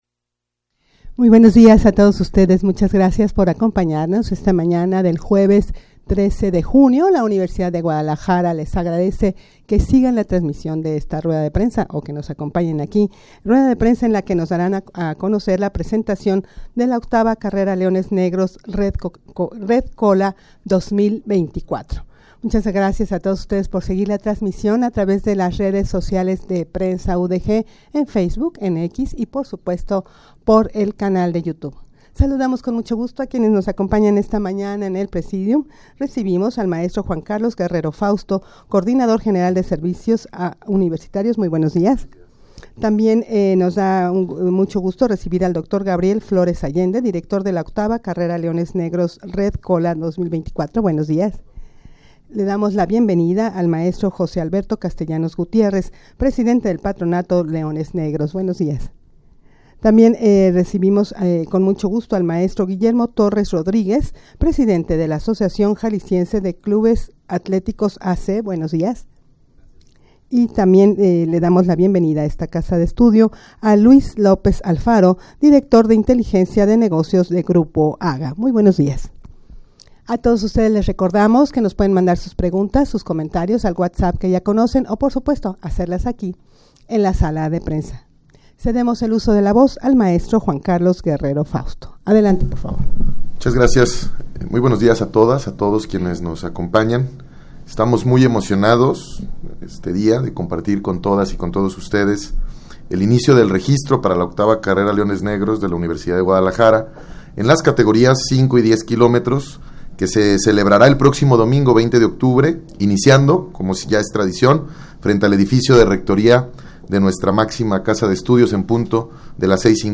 Audio de la Rueda de Prensa
rueda-de-prensa-la-que-se-daran-a-conocer-todos-los-detalles-de-la-octava-carrera-leones-negros.mp3